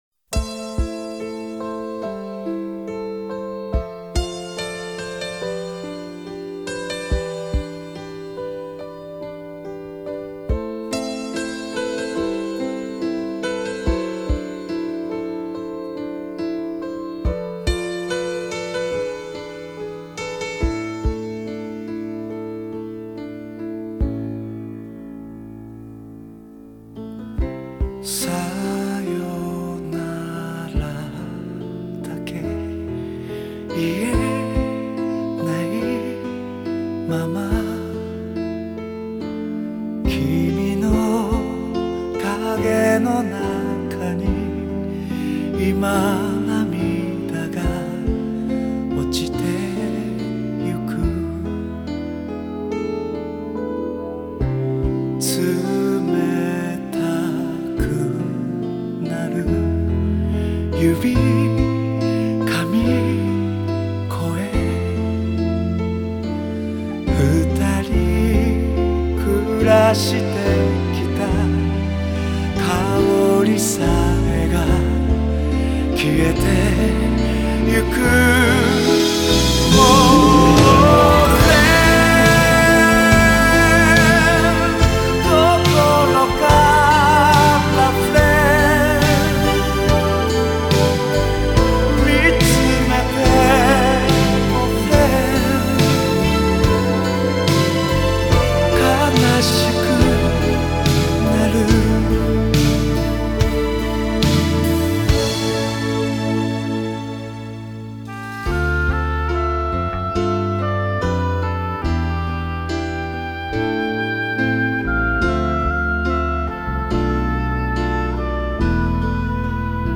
原唱版